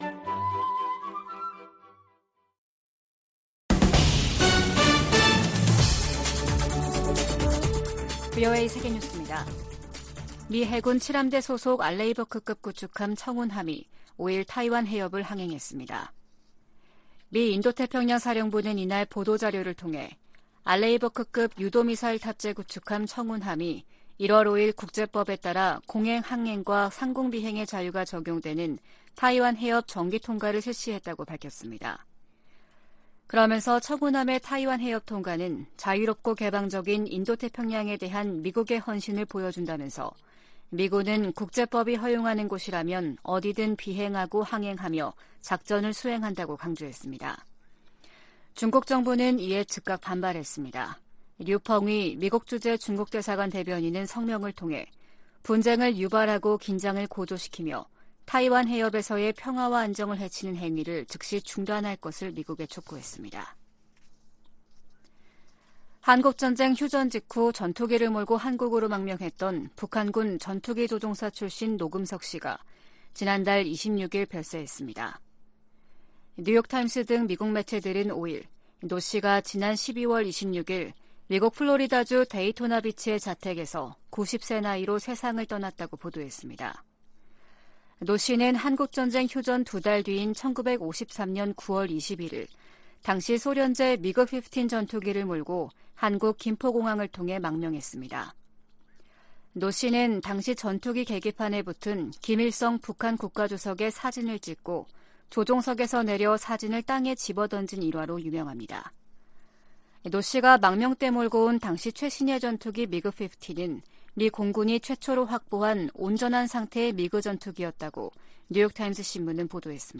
VOA 한국어 아침 뉴스 프로그램 '워싱턴 뉴스 광장' 2023년 1월 7일 방송입니다. 미국과 일본이 워싱턴에서 외교 국방장관 회담을 개최한다고 미 국무부가 발표했습니다. 미 국방부가 북한의 핵탄두 보유량 증대 방침에 대한 우려를 표시했습니다.